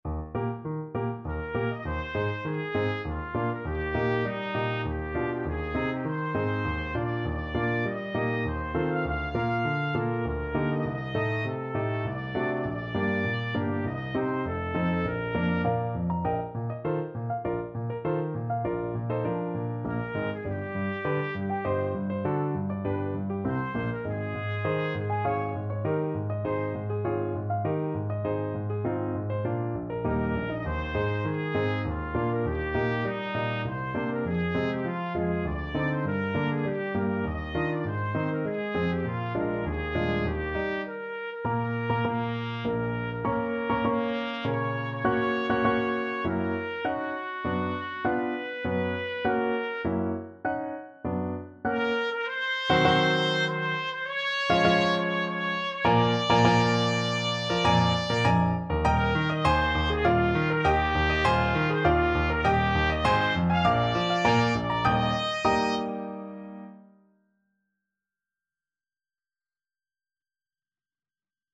Trumpet
3/4 (View more 3/4 Music)
~ = 100 Tempo di Menuetto
Eb major (Sounding Pitch) F major (Trumpet in Bb) (View more Eb major Music for Trumpet )
Classical (View more Classical Trumpet Music)